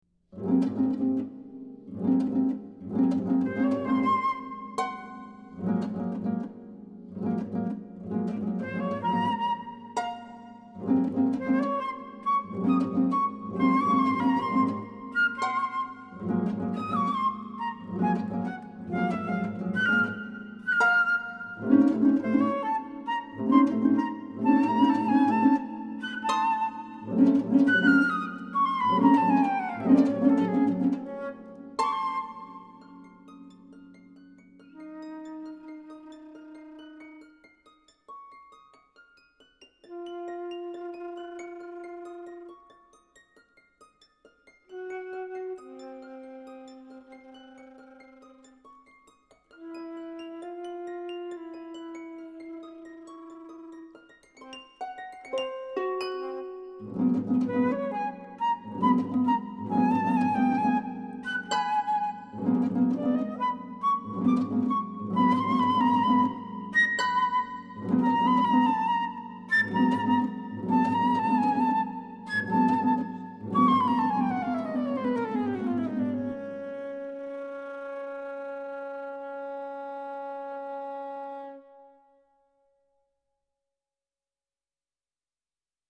flute and harp